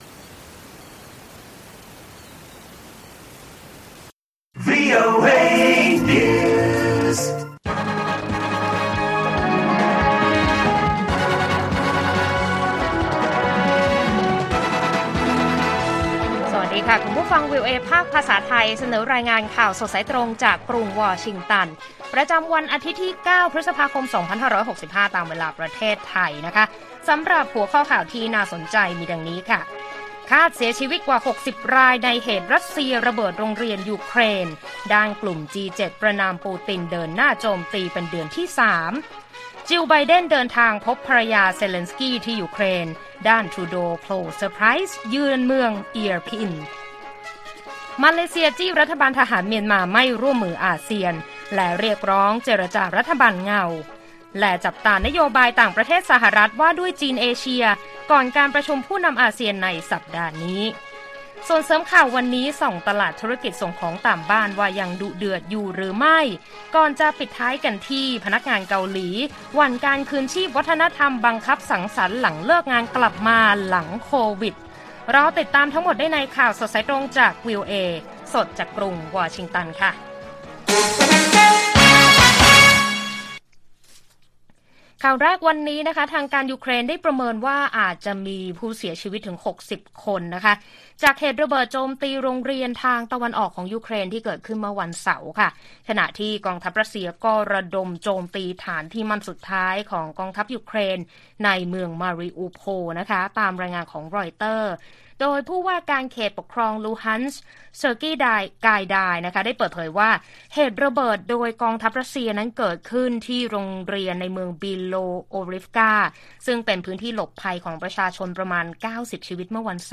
ข่าวสดสายตรงจากวีโอเอไทย วันที่ 9 พ.ค. 2565